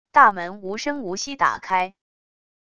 大门无声无息打开wav音频